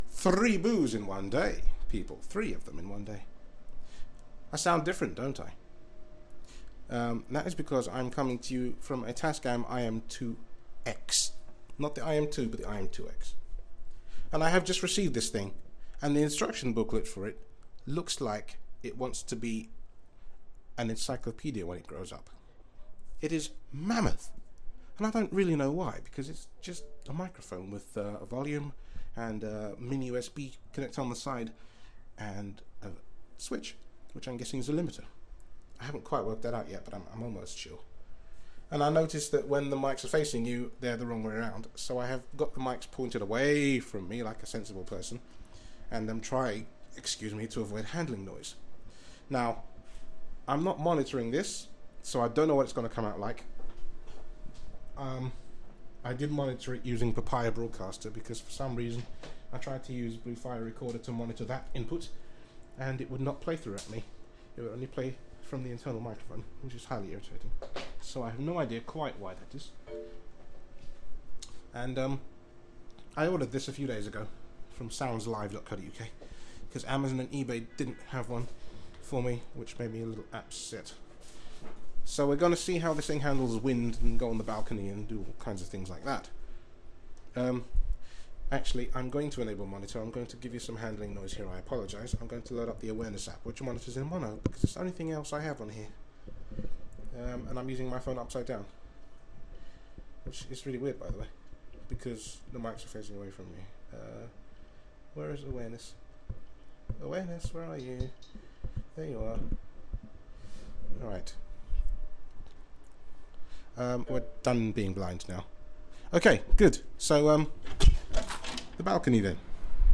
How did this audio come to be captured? Tascam IM-2X, and if I'm not too blind, picture of Jawbone Jambox boo: Share Facebook X Next Further testing with the tascam. I am on the iPad this time.